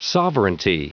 Prononciation du mot sovereignty en anglais (fichier audio)